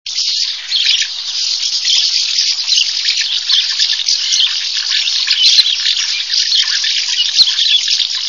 Jaskółka oknówka - Delichon urbicum